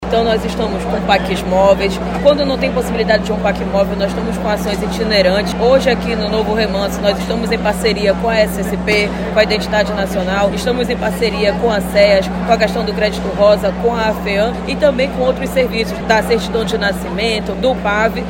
De acordo com a secretária titular da SEJUSC, Jussara Pedrosa, a atividade conta com parceria de outras secretarias e visa levar facilidade para a população.